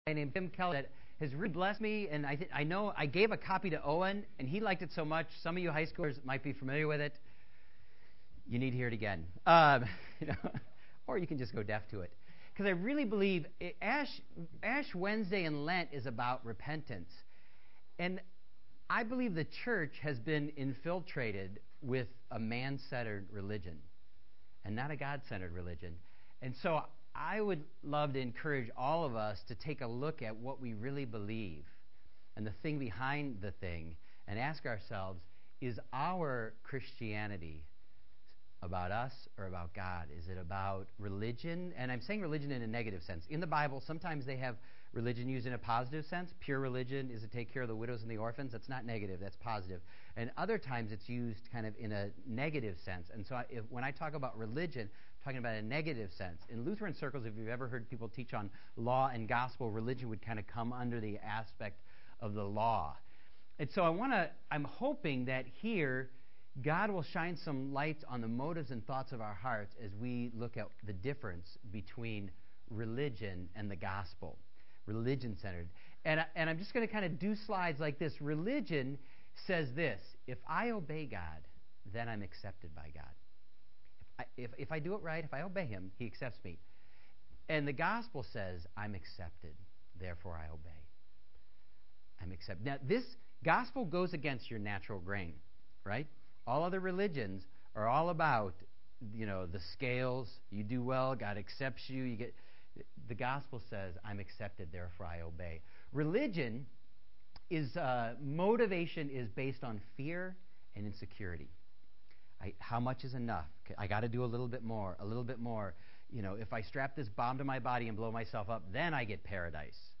Find a previous sermon | Subscribe to COH's Sermon Podcast